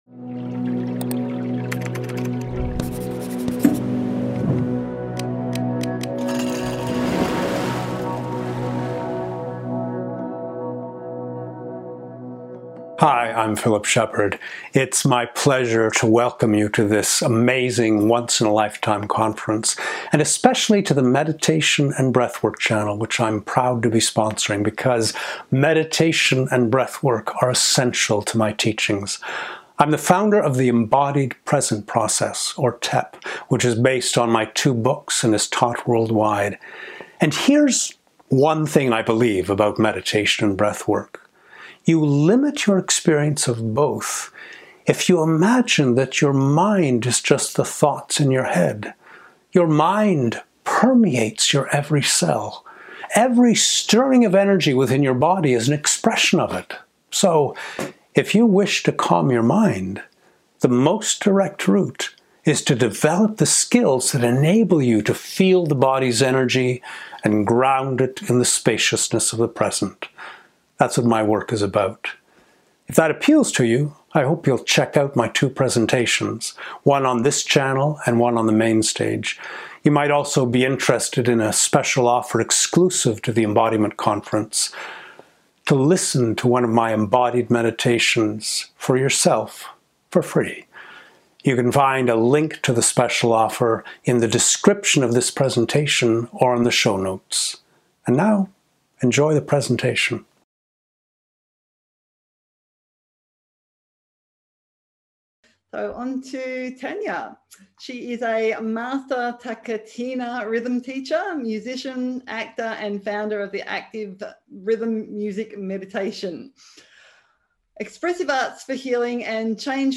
Intermediate understanding Some standing/ movement Likely neutral A dynamic, experiential TaKeTiNa session, where the body becomes the instrument, and guided rhythm journeys invite a tangible experience of ' Order on the Edge of Chaos', opening states of timelessness, deep rhythmic flow, and stillness of mind.
Adding the Voice to the Rhythm